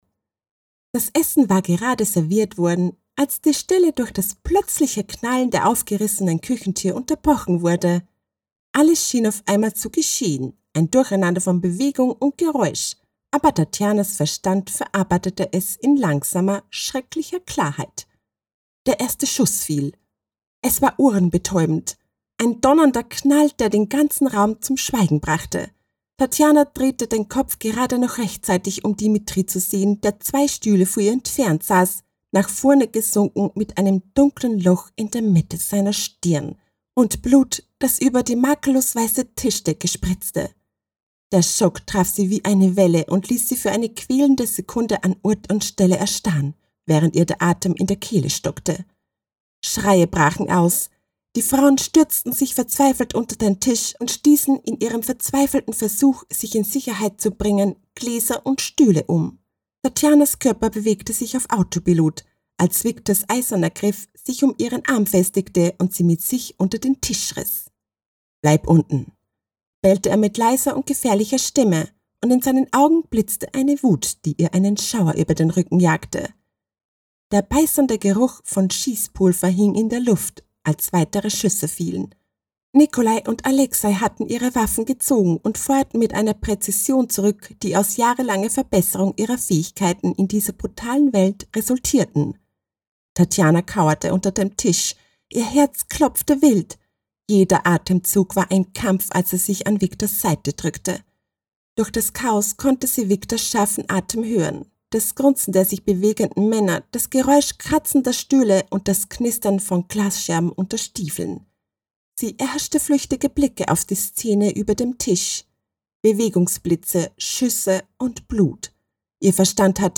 Female
Österreichisches Deutsch, warmherzig, freundlich, charmant, beruhigend, energisch
Audiobooks